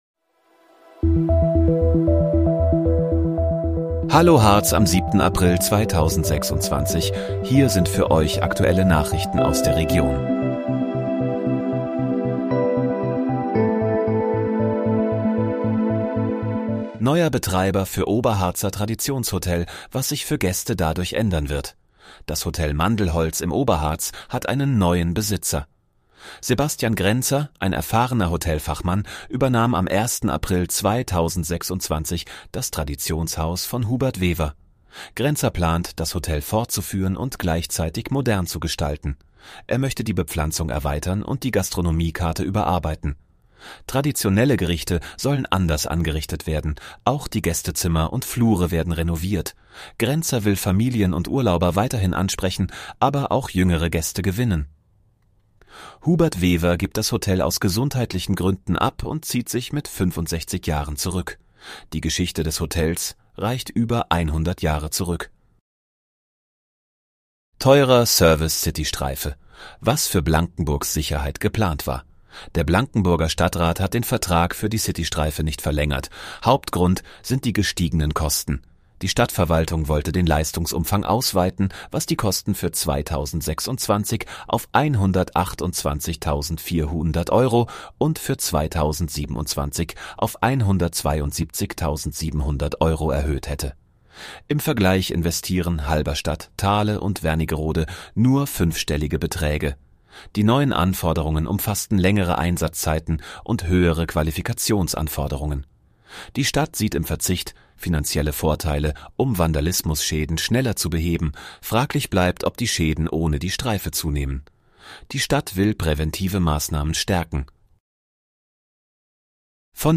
Hallo, Harz: Aktuelle Nachrichten vom 07.04.2026, erstellt mit KI-Unterstützung